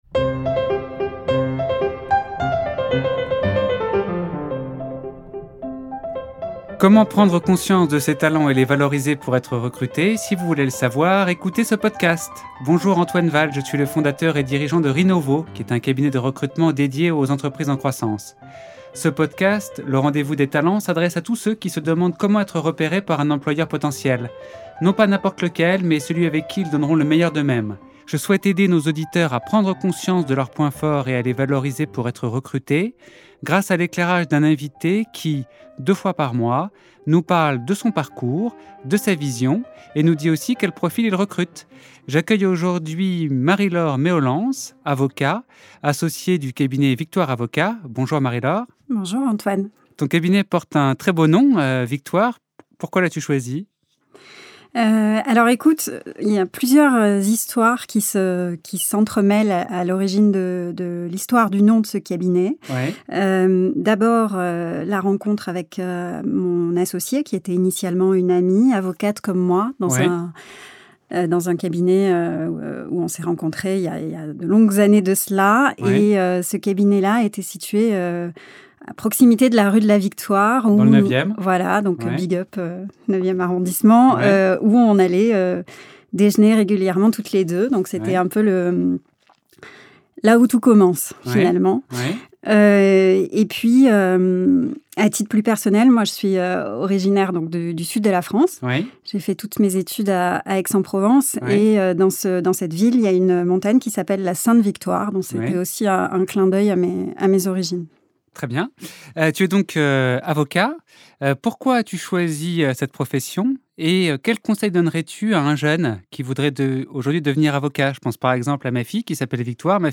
Pas évident de se plier au jeu de l’interview.